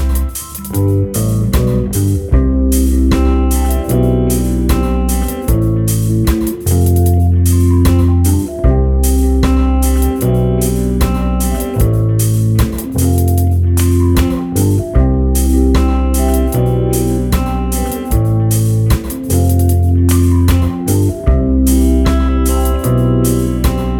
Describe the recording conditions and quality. Live Version Reggae 4:16 Buy £1.50